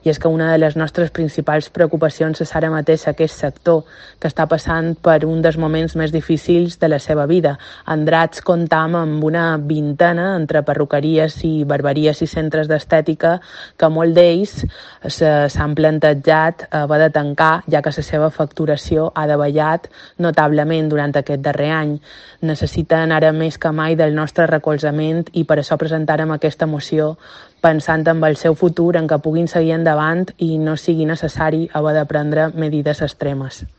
Joana Dolç, concejal del PP de Andratx